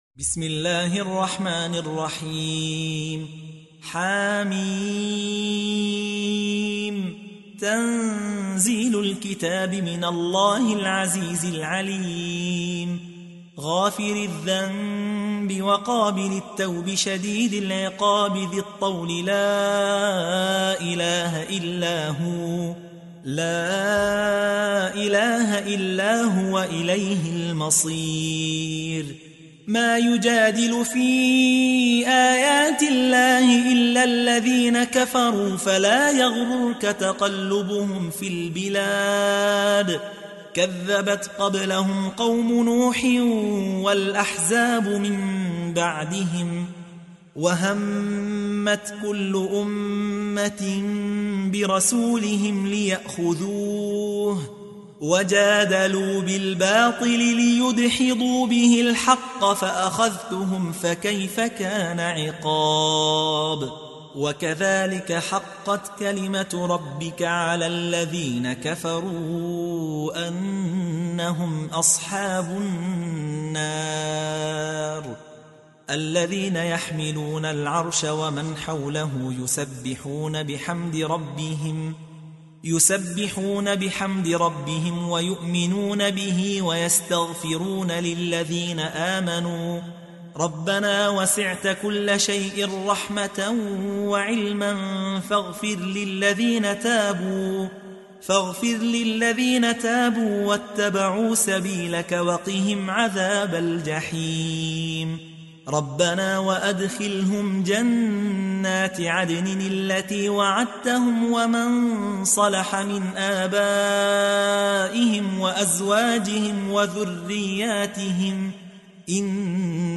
تحميل : 40. سورة غافر / القارئ يحيى حوا / القرآن الكريم / موقع يا حسين